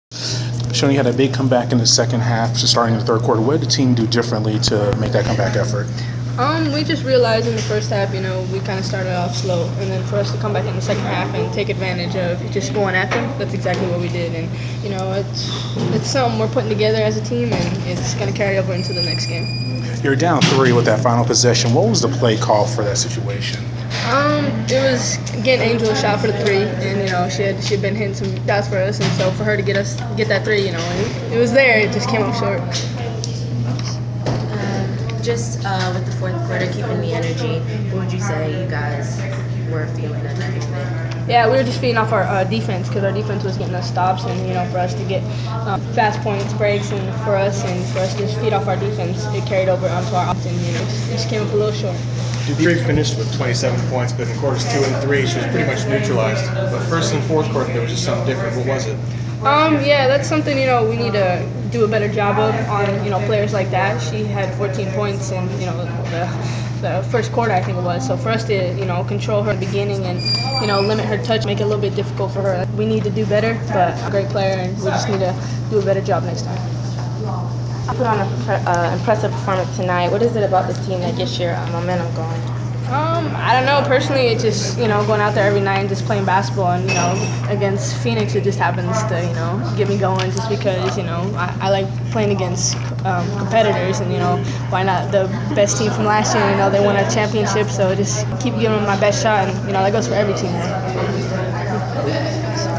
Inside the Inquirer: Postgame interview with Atlanta Dream player Shoni Schimmel 8.2.15
The Sports Inquirer attended the post-game presser of Atlanta Dream guard Shoni Schimmel following her team’s 71-68 home loss to the Phoenix Mercury on August 2.